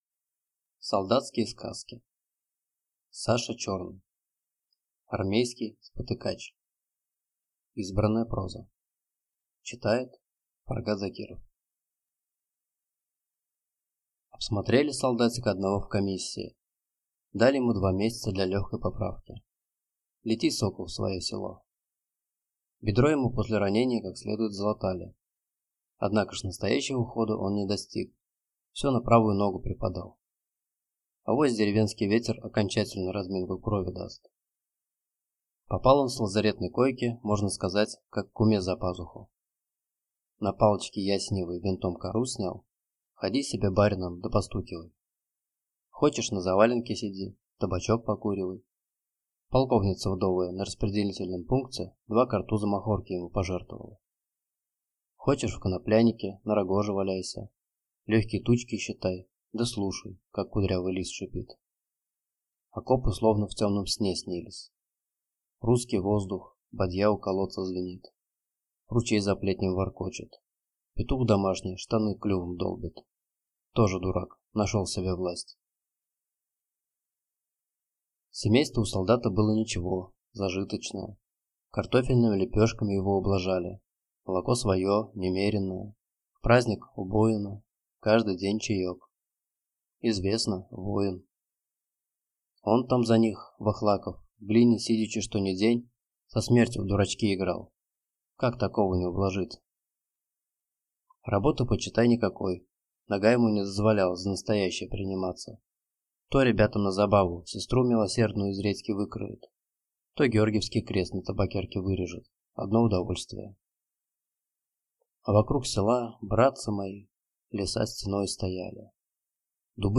Аудиокнига Армейский спотыкач | Библиотека аудиокниг